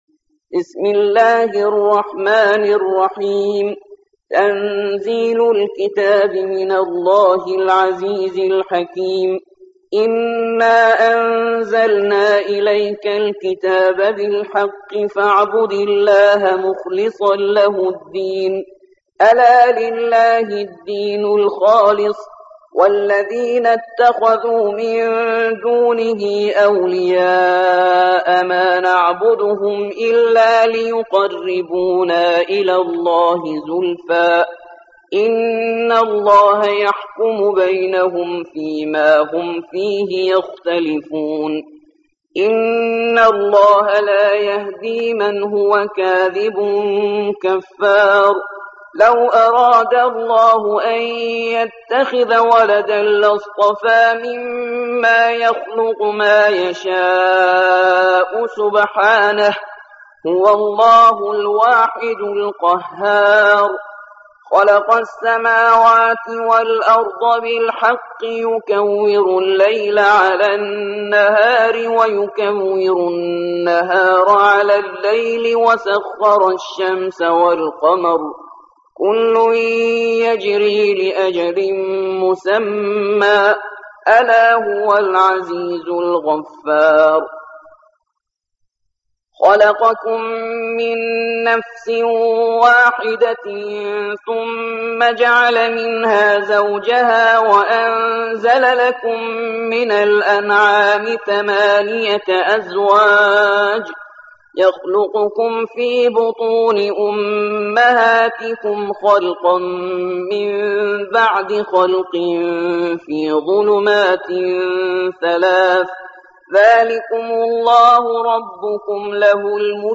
39. سورة الزمر / القارئ